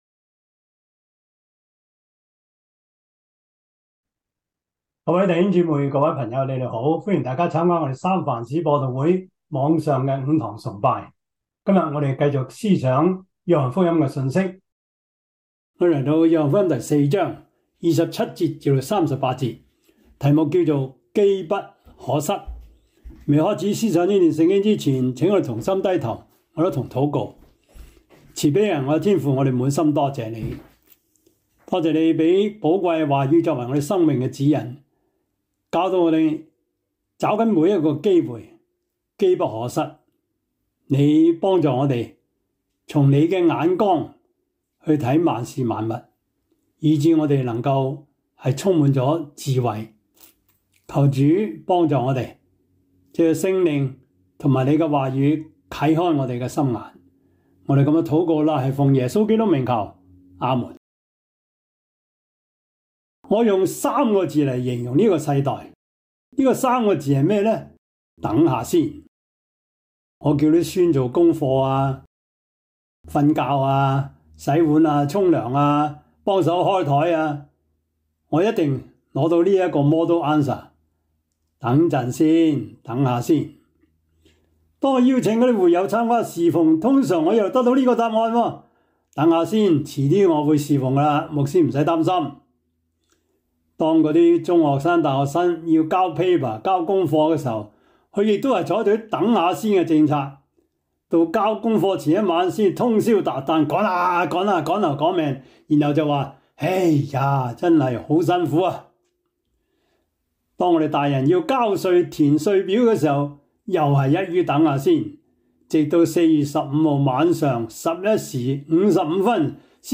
約翰福音 4:27-38 Service Type: 主日崇拜 約翰福音 4:27-38 Chinese Union Version
」 Topics: 主日證道 « 耶穌就職典禮 基礎神學 (二)- 第九課 »